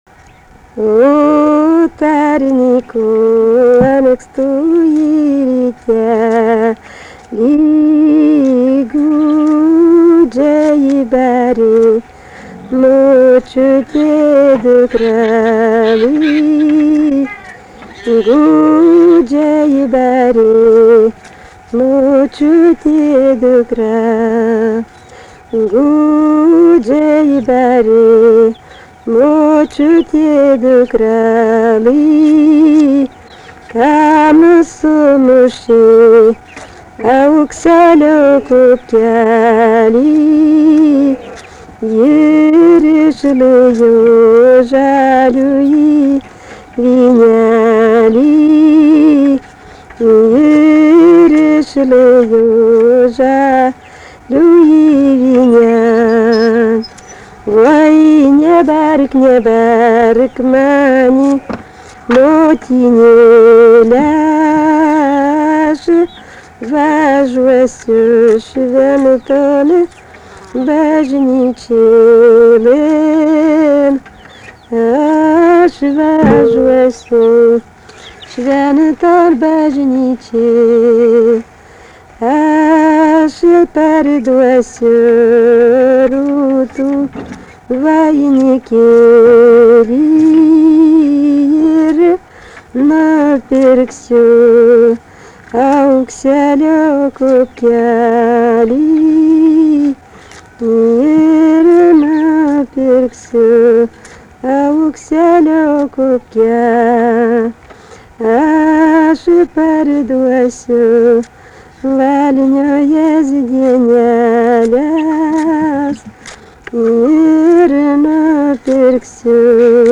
Dalykas, tema daina
Erdvinė aprėptis Kašėtos
Atlikimo pubūdis vokalinis